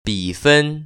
[bĭfēn] 비펀  ▶